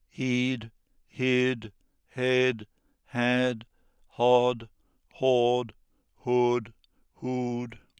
in my normal voice
whispered
in a creaky voice